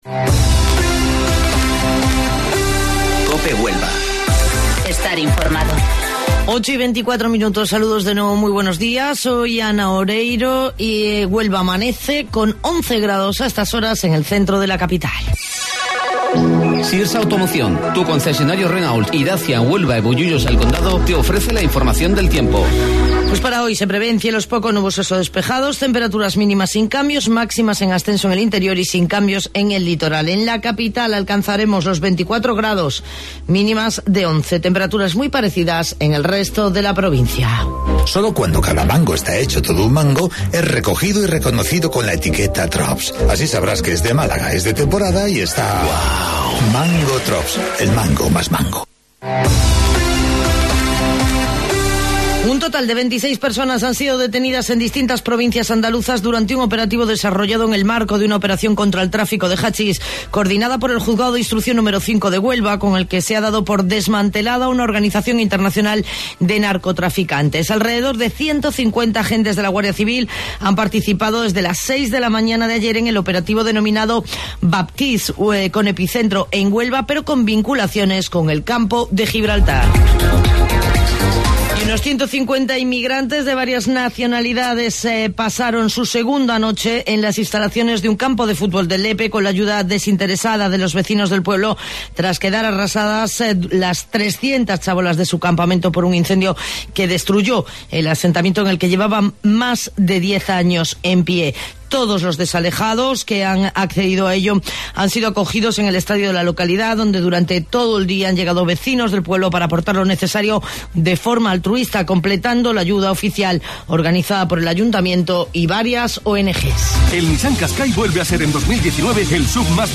AUDIO: Informativo Local 08:25 del 16 de Octubre